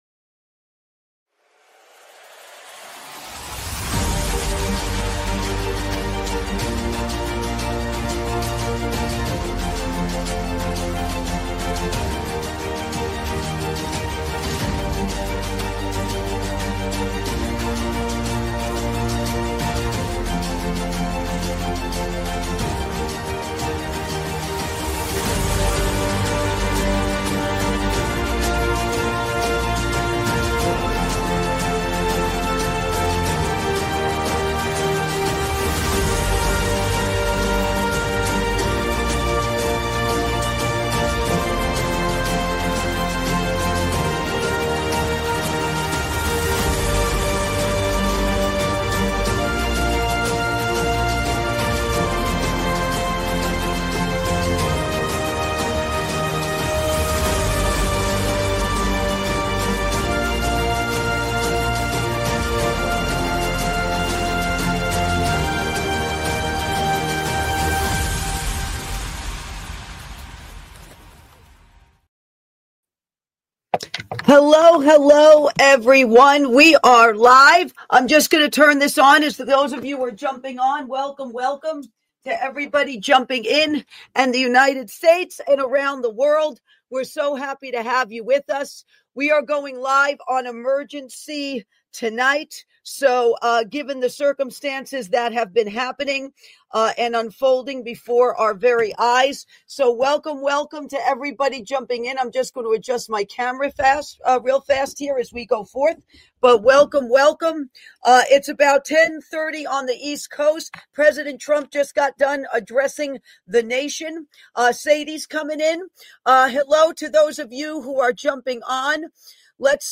LIVE REACTION The United States Bombs IRAN
LIVE REACTION: THE UNITED STATES BOMBS IRAN!